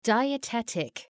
Shkrimi fonetik {,daıə’tetık}